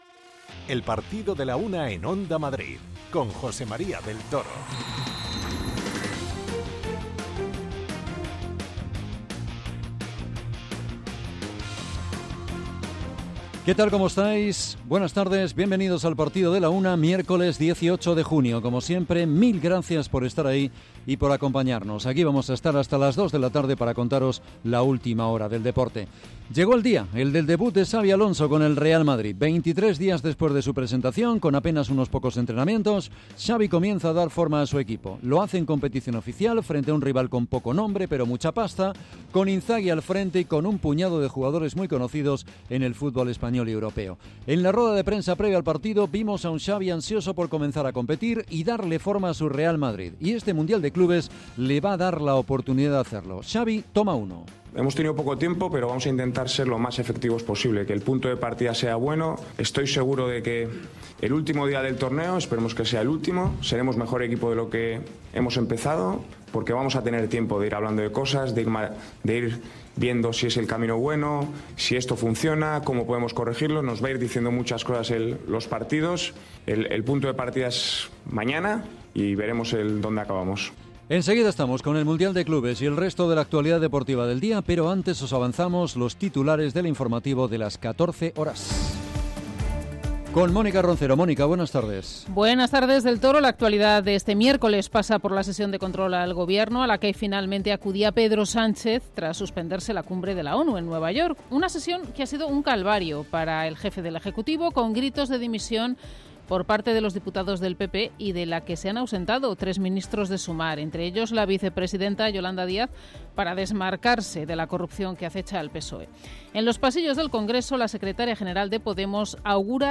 Escuchamos a su entrenador, Simone Inzaghi.